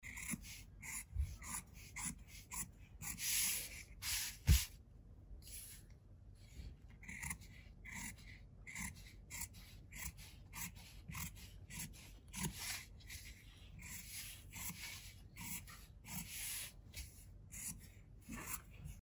Звуки скатерти
10. Техника разрезания ткани ножницами